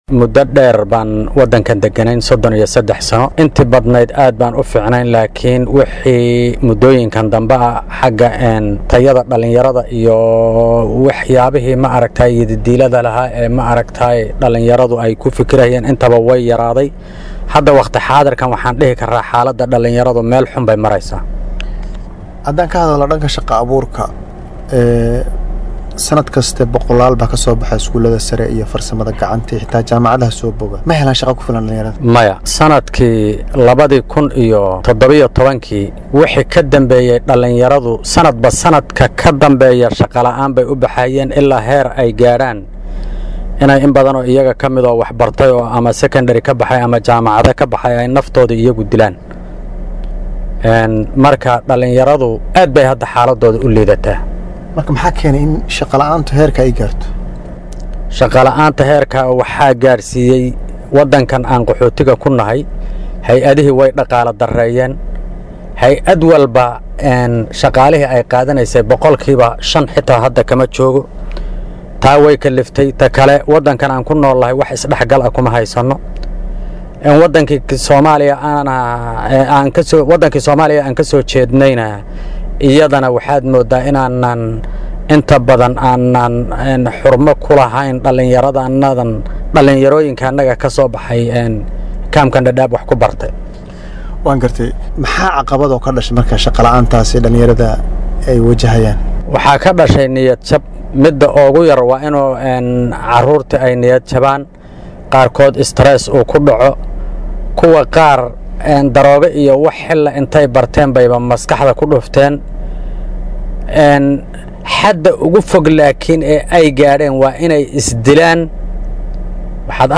Wareysi-Dadaab.mp3